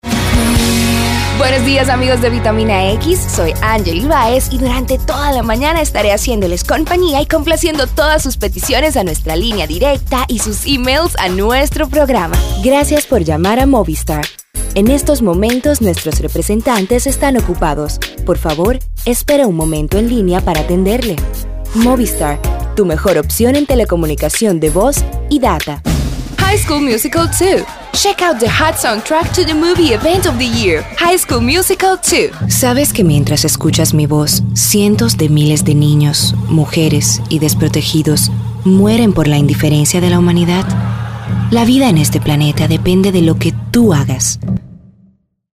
Voz Clara, Fresca, Juvenil, Amigable, Institucional, Español Neutro.
Smooth and clear vioce. Spanish Neutral. Good diction.